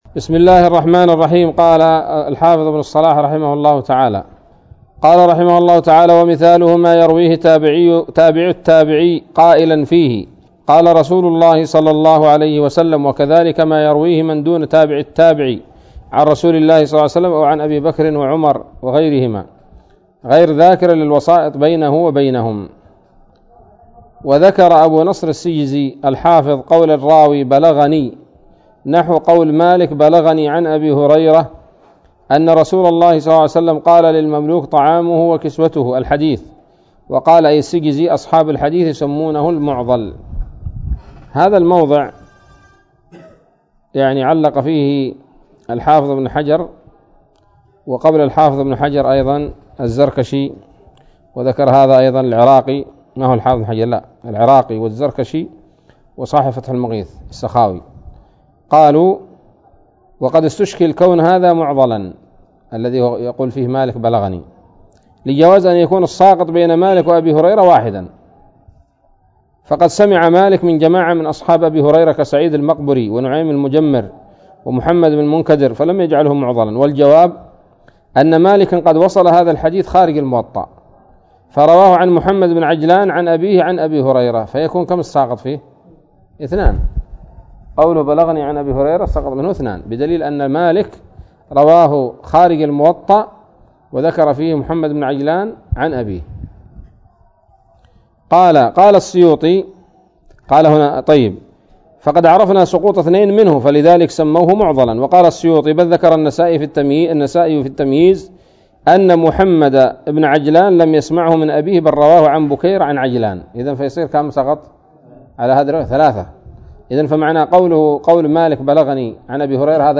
الدرس السابع والعشرون من مقدمة ابن الصلاح رحمه الله تعالى